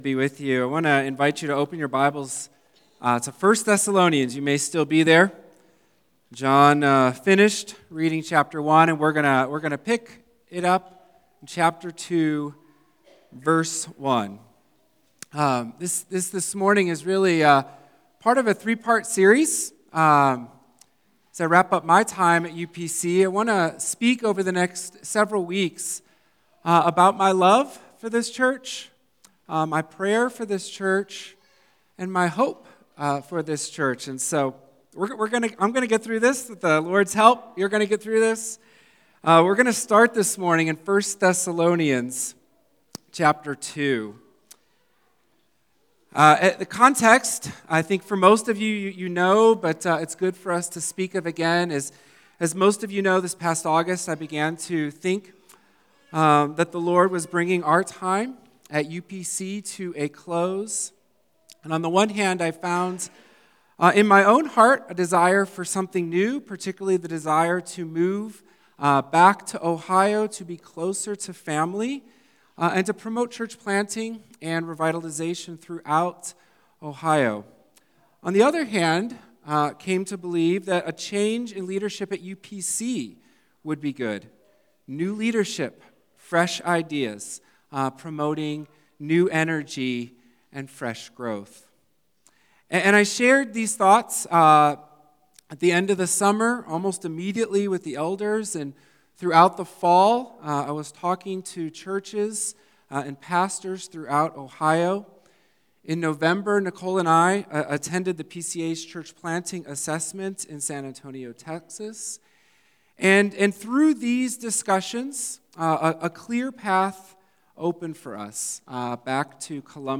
1 Thessalonians Passage: 1 Thessalonians 2:1-8 Service Type: Sunday Worship « 2024